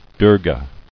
[Dur·ga]